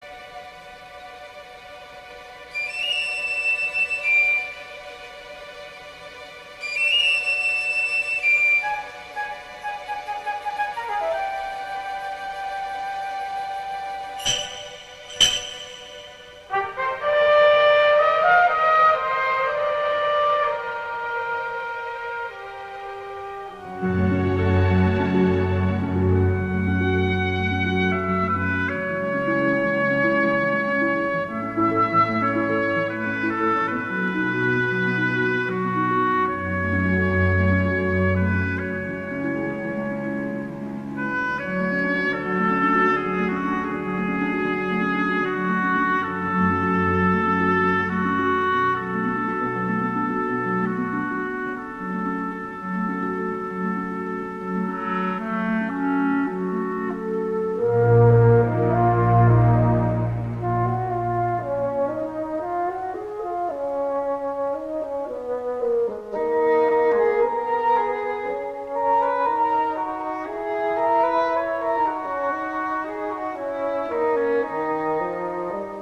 una partitura de excepcional serenidad y delicadeza